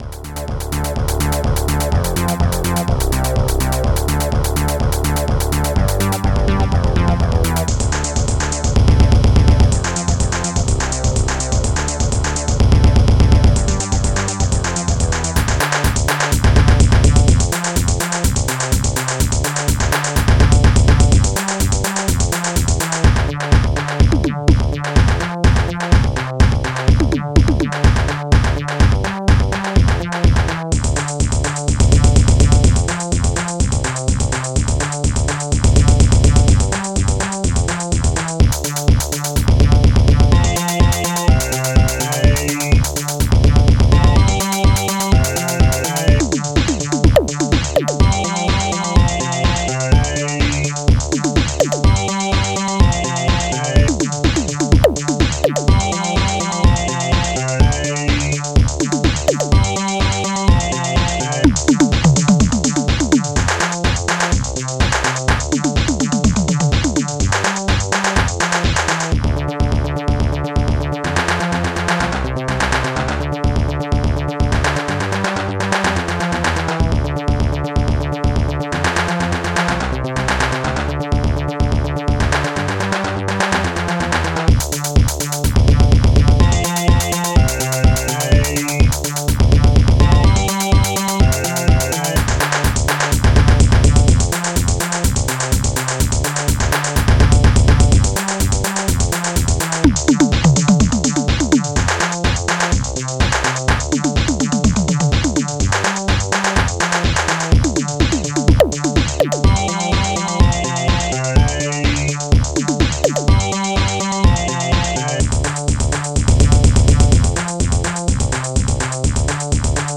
Protracker Module
Instruments dxtom hihat2 smash1 bassdrum3 rubberbass theegg claps1 hihat2 wowbass